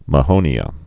(mə-hōnē-ə)